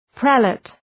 Προφορά
{‘prelıt}